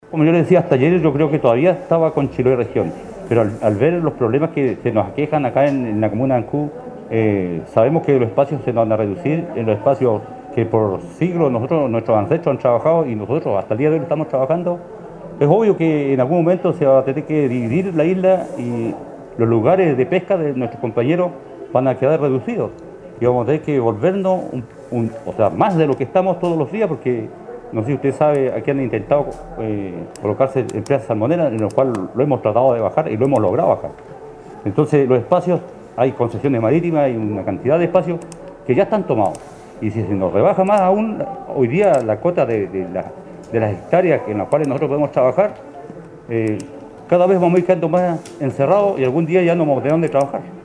el pescador artesanal